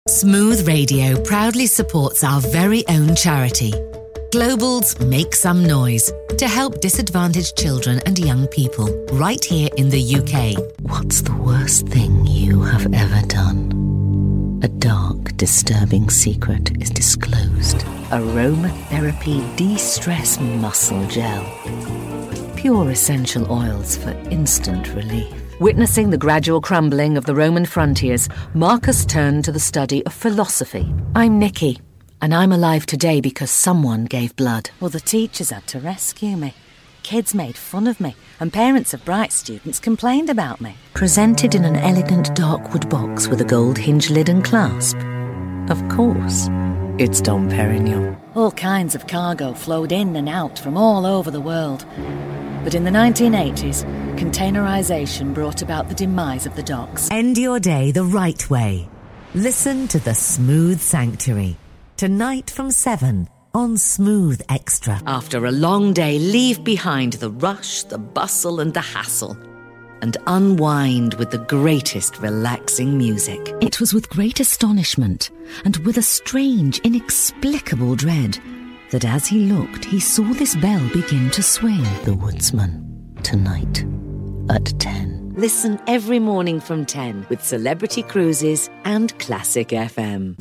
VOICE OVER DEMO REEL